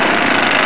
jackhammer.wav